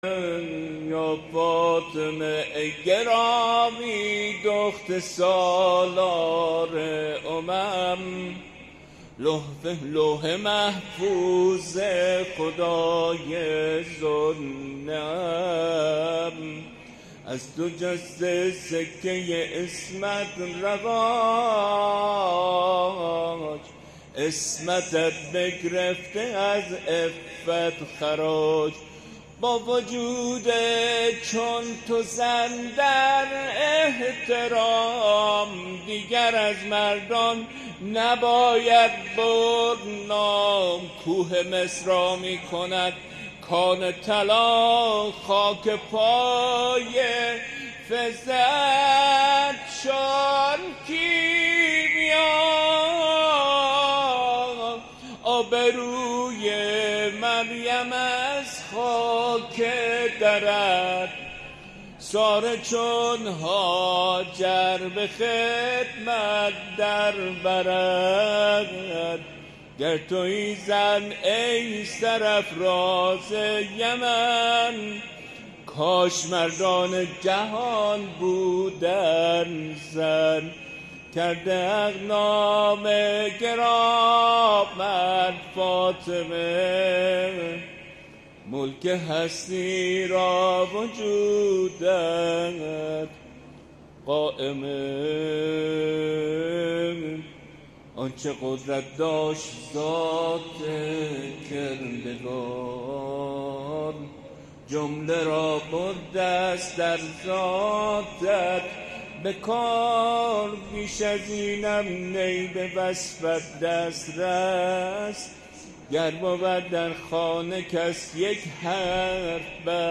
ذاکر و شاعر اهل بیت علیهم السلام